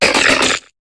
Cri de Mysdibule dans Pokémon HOME.